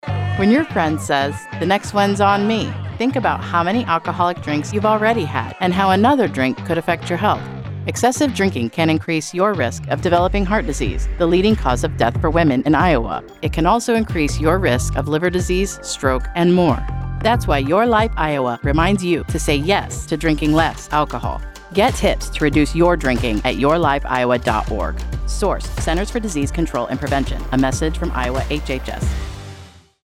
:30 Radio Spot | Female